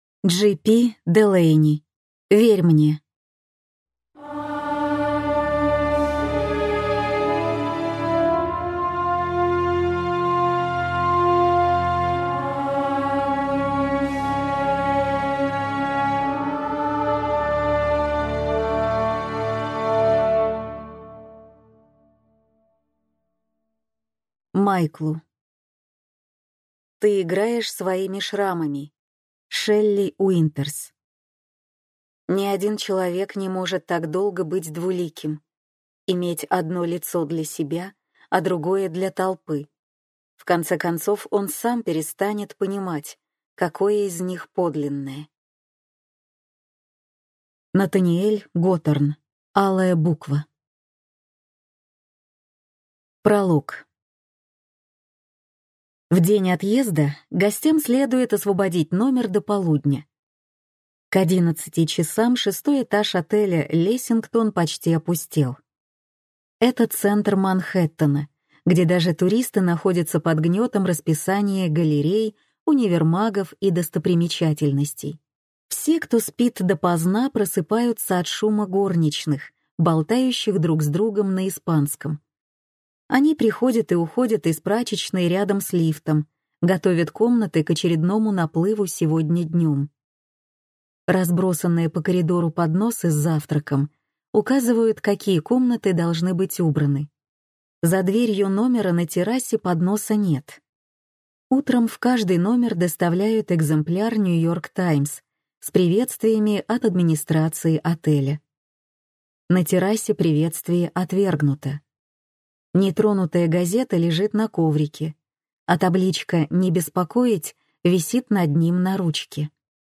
Аудиокнига Верь мне | Библиотека аудиокниг